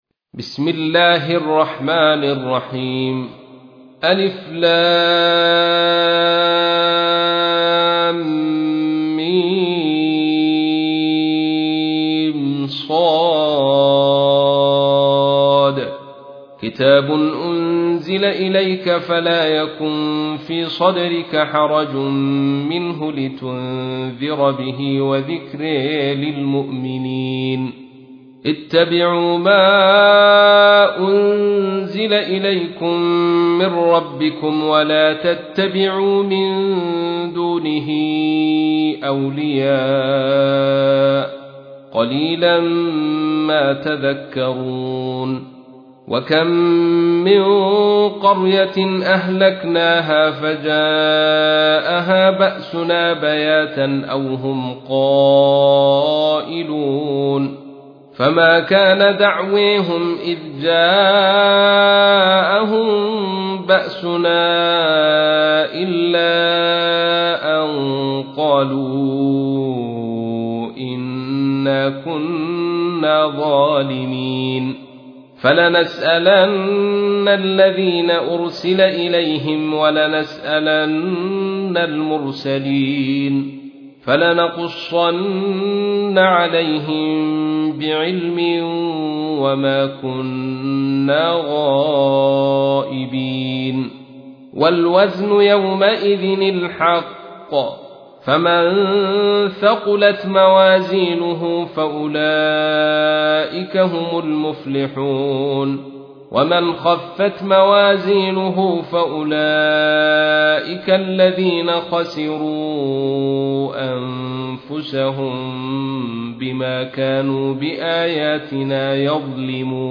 تحميل : 7. سورة الأعراف / القارئ عبد الرشيد صوفي / القرآن الكريم / موقع يا حسين